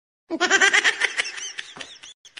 All Goofy Ahh Effects For 2023 Memes